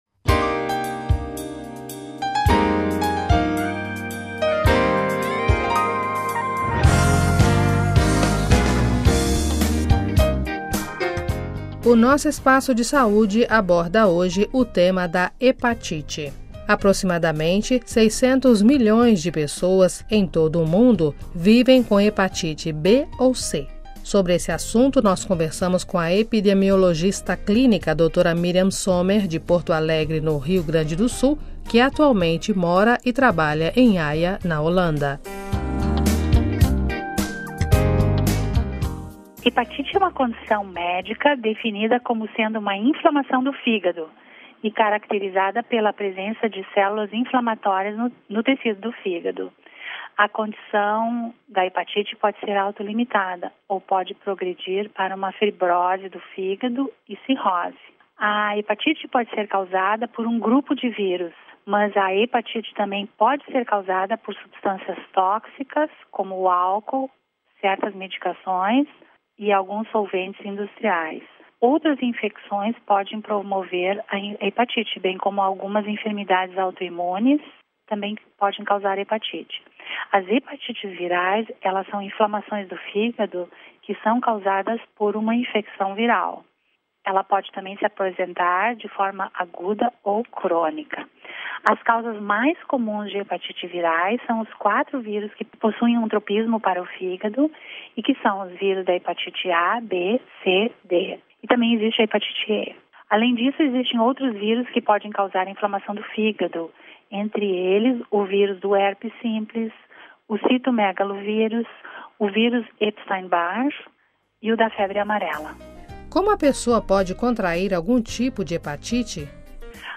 Sobre esse assunto nós conversamos com a epidemiologista clínica